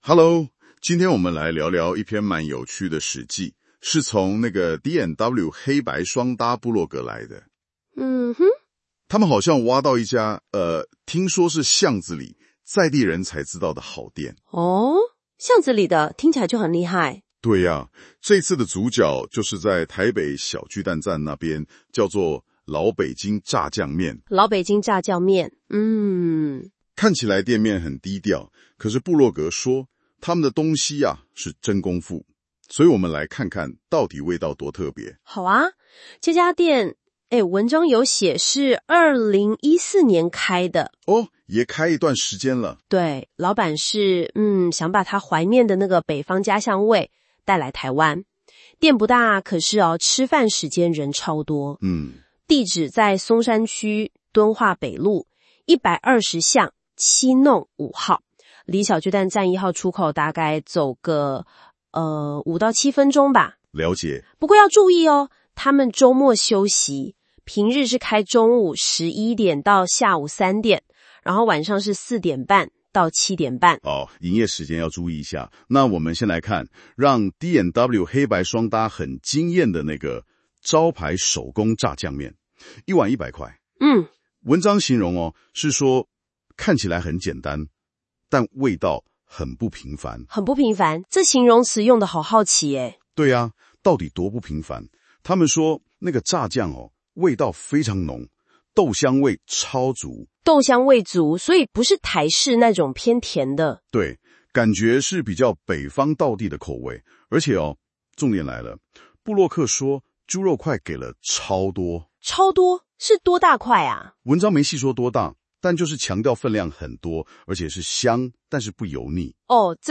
新功能!現在用【說】的方式介紹文章哦!
我們請兩位主持人專業講解，介紹D&W黑白雙搭本文章